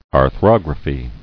[ar·throg·ra·phy]